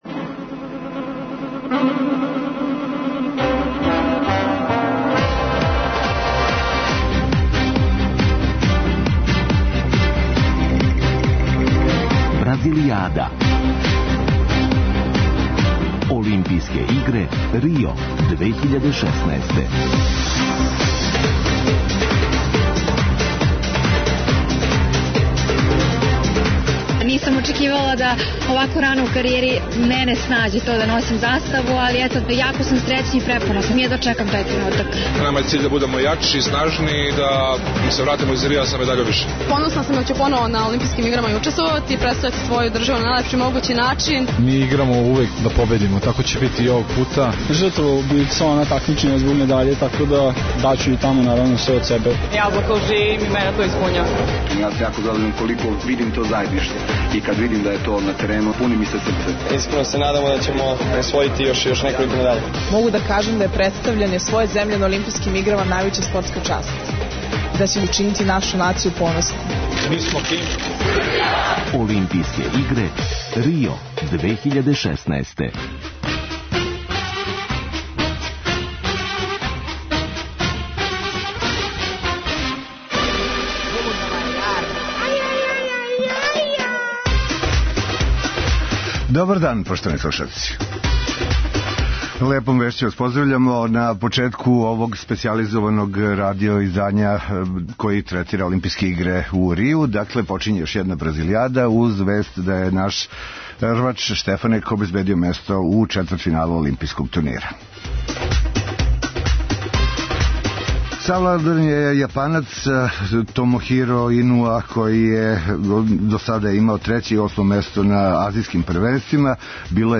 Специјална емисија која ће се емитовати током трајања Олимпијских игара у Рију. Пратимо наше спортисте који учествују на ОИ, анализирамо мечеве, уз госте у Студију 1 Радио Београда и укључења наших репортера са лица места.
У оквиру данашње емисије преносимо четвртфинални меч за кошаркашице: Србија - Аустралија и четвртфинални меч за ватерполисте: Србија - Шпанија.